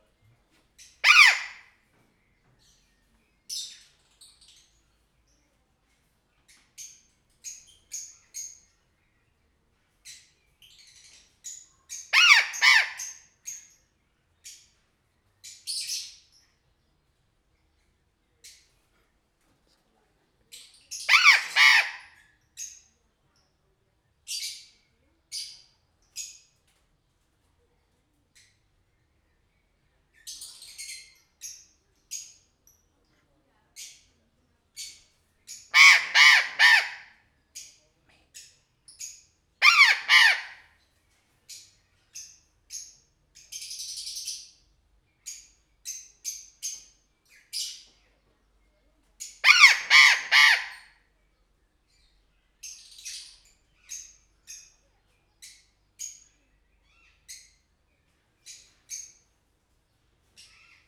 sziklapapagaj_jobbraafrikaitorpepapagaj.WAV